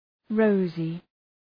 Προφορά
{‘rəʋzı}